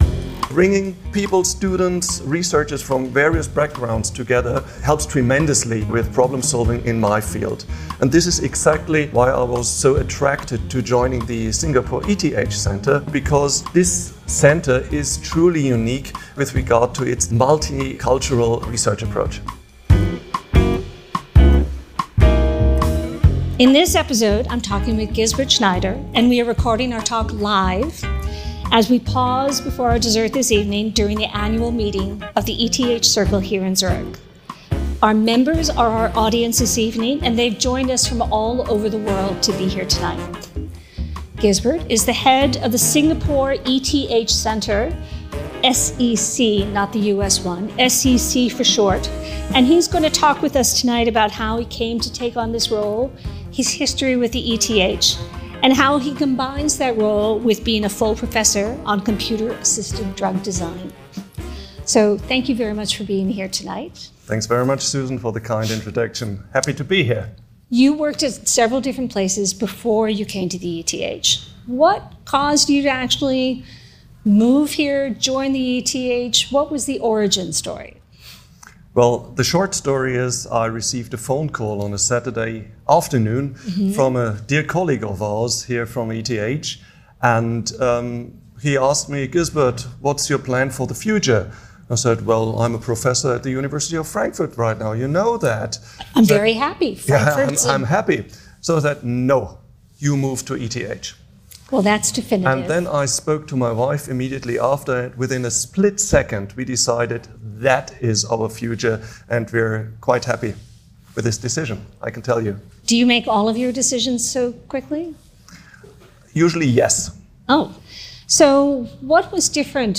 On the occasion of the ETH Circle Annual Meeting, we bring you a special live recording of the We Are ETH Podcast.